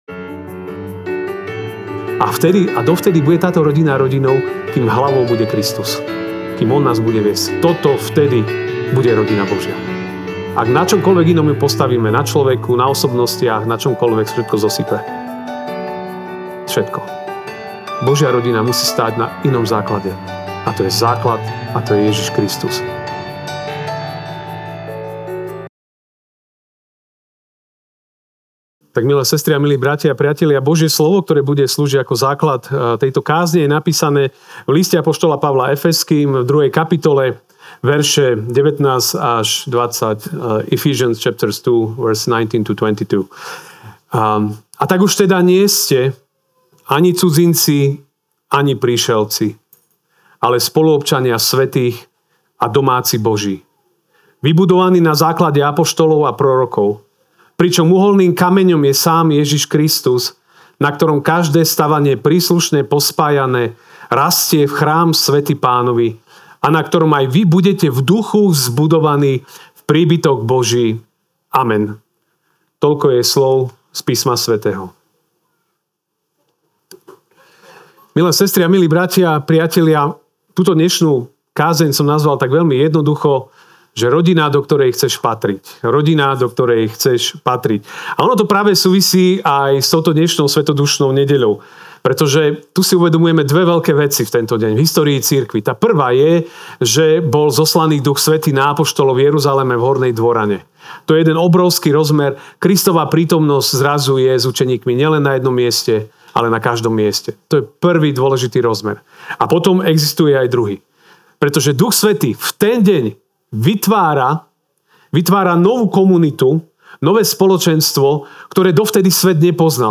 Ranná kázeň